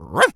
dog_small_bark_02.wav